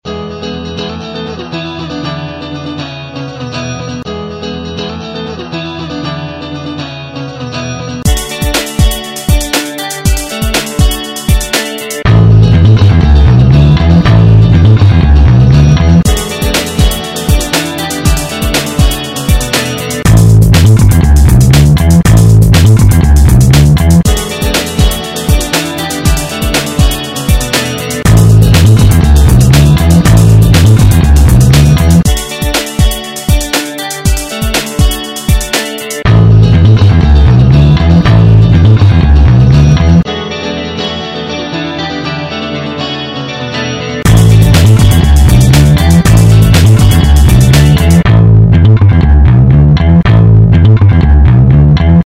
My favorite program for this is called Acid Pro, and it allows you to use music "loops" to compose music.